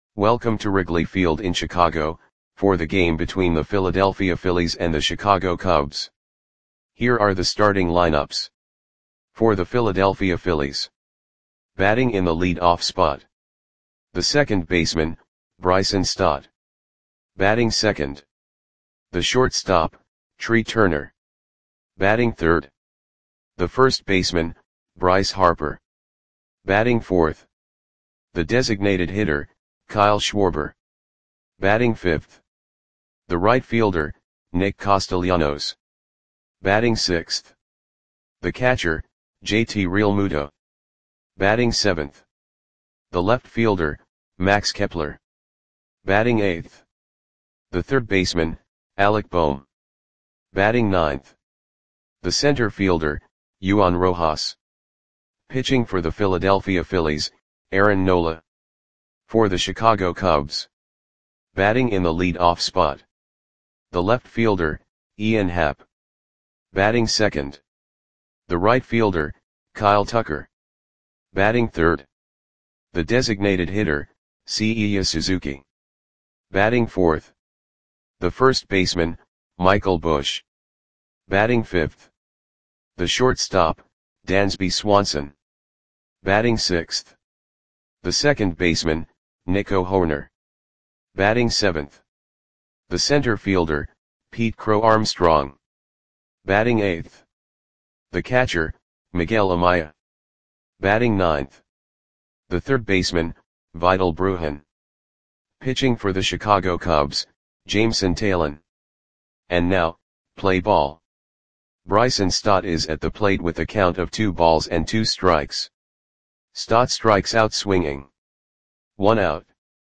Audio Play-by-Play for Chicago Cubs on April 27, 2025
Click the button below to listen to the audio play-by-play.